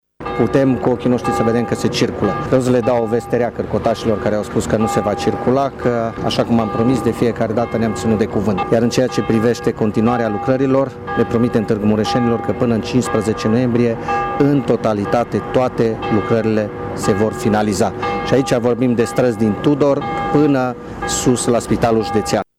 Săptămâna aceasta vor mai intra în şantiere părţi din Blvd. 1 decembrie 1918 şi străzi din cartierul Tudor, a arătat astăzi viceprimarul Claudiu Maior: